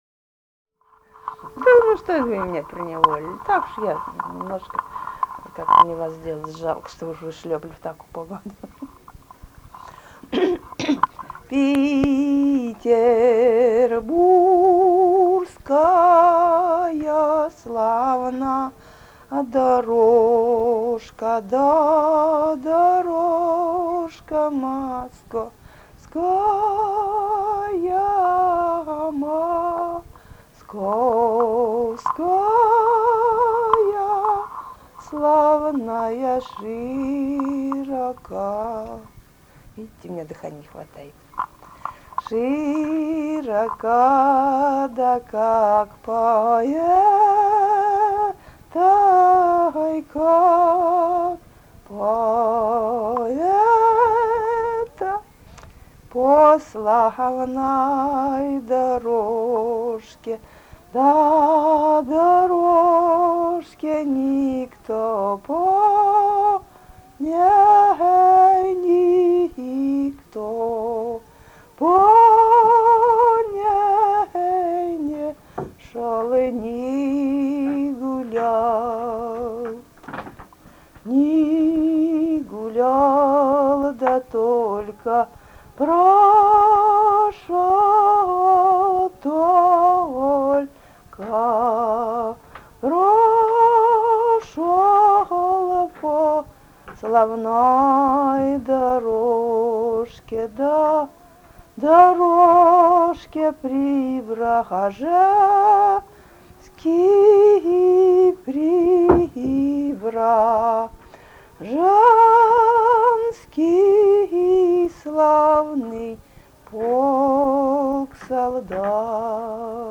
Русские народные песни Владимирской области 17б. Петербургская славна дорожка (протяжная рекрутская) с. Мстёра Вязниковского района Владимирской области.